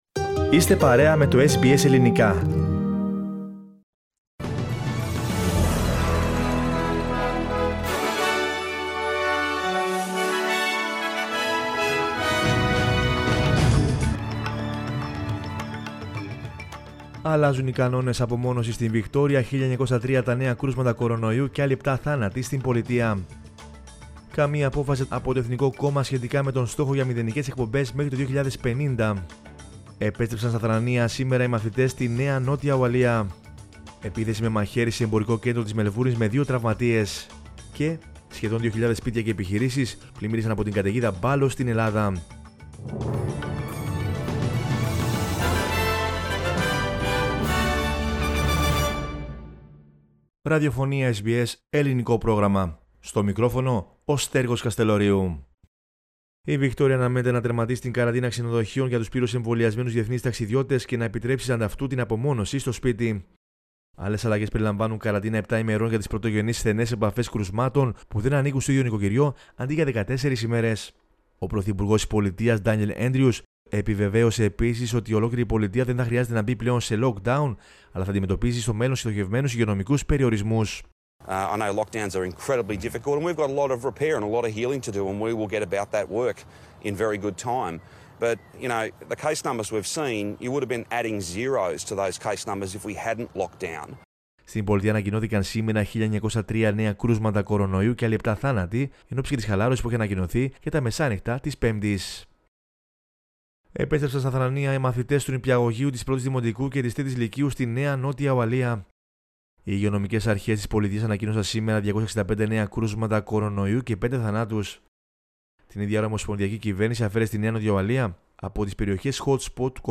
News in Greek from Australia, Greece, Cyprus and the world is the news bulletin of Monday 18 October 2021.